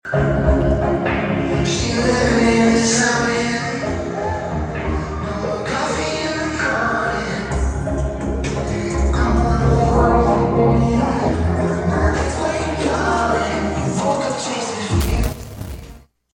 Two-plus decades of rap and R&B came to the Emporia Granada on Saturday